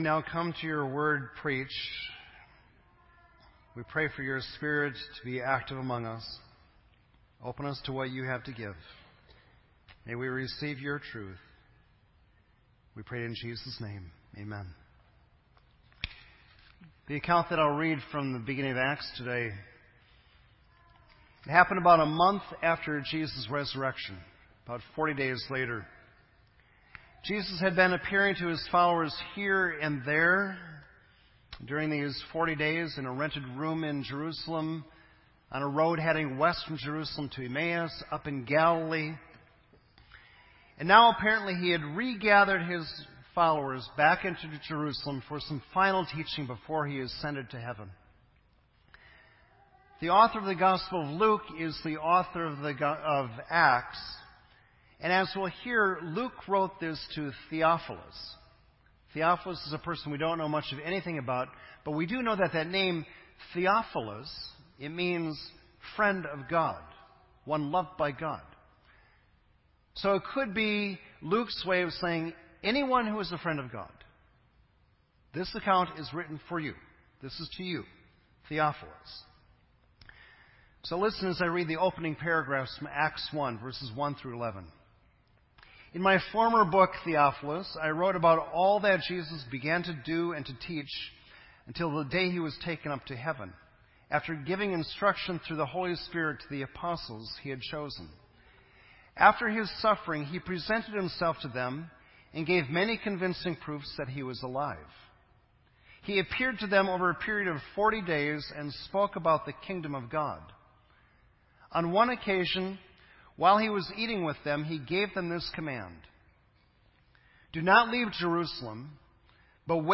This entry was posted in Sermon Audio on May 2